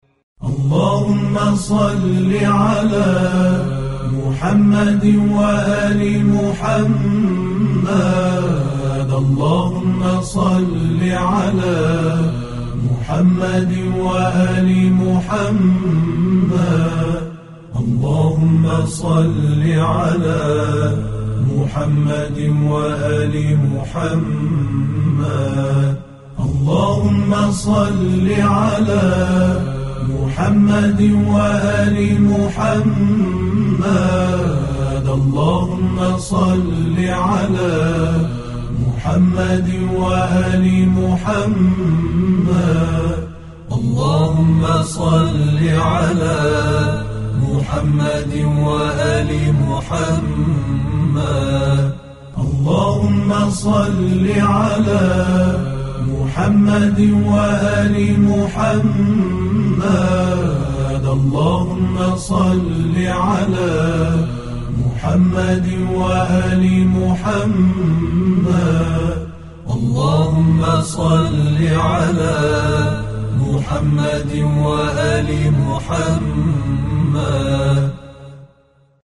گالری صوتی صلوات
Sounds of Salavaat
صلوات گروه طوبی